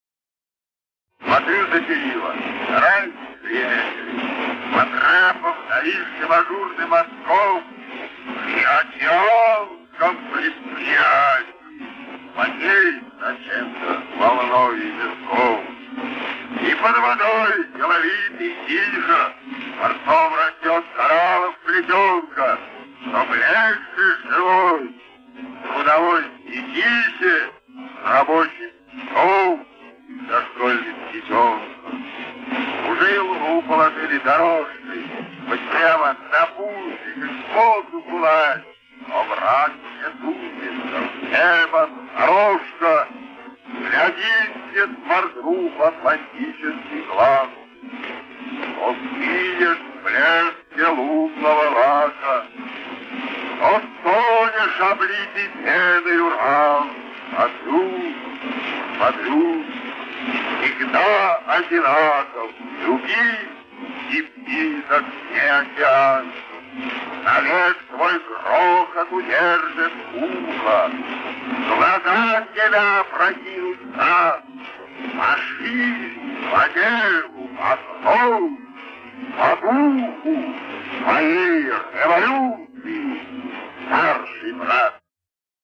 1. «Владимир Маяковский – Атлантический океан (отрывок) (читает автор)» /
Mayakovskiy-Atlanticheskiy-okean-otryvok-chitaet-avtor-stih-club-ru.mp3